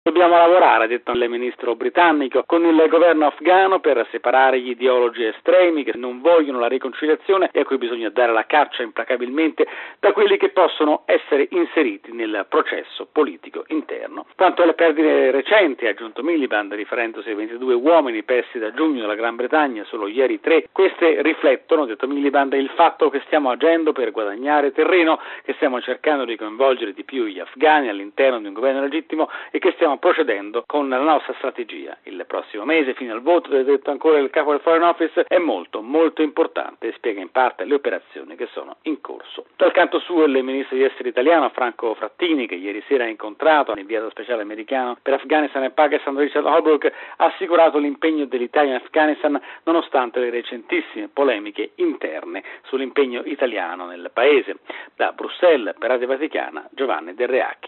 Inoltre, secondo quanto detto dal ministro degli Esteri britannico, David Miliband, per stabilizzare l’Afghanistan occorre un forte impegno per la riconciliazione nazionale, con il coinvolgimento nelle istituzioni dei talebani moderati. Il servizio da Bruxelles: RealAudio